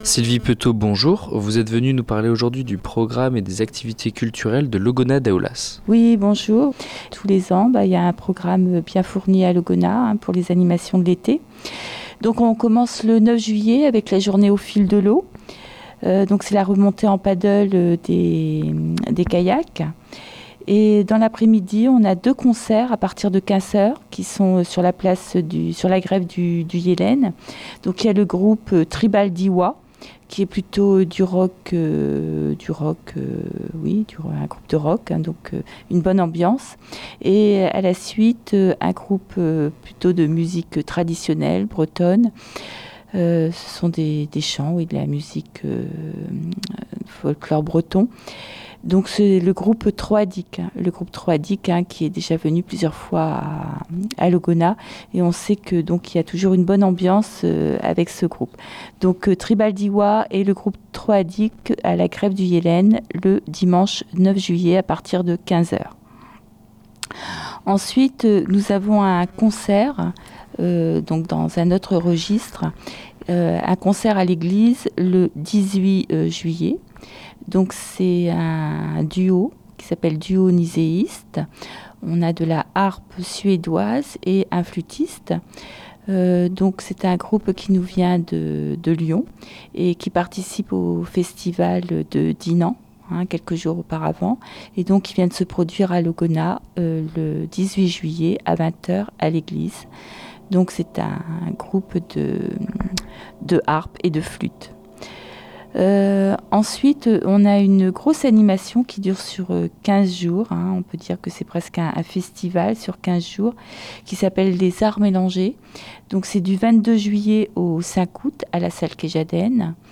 Réécoutez l'interview de Sylvie Peteau, adjointe à la culture